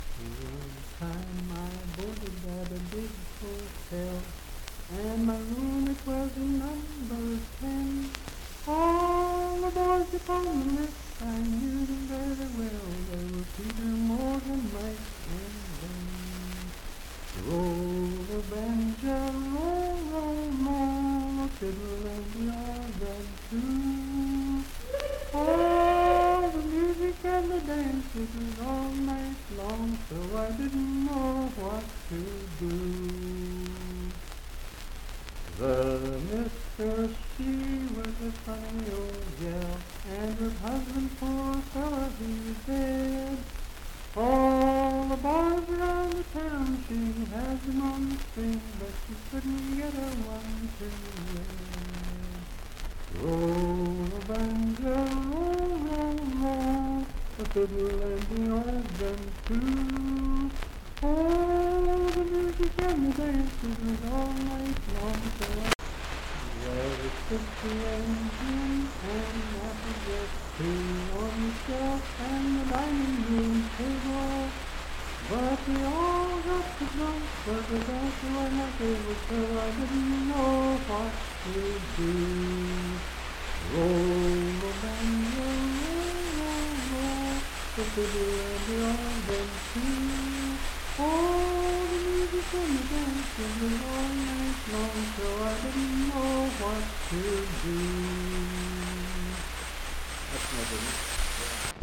Unaccompanied vocal music
Verse-refrain 3(4) & R(4).
Voice (sung)
Pocahontas County (W. Va.)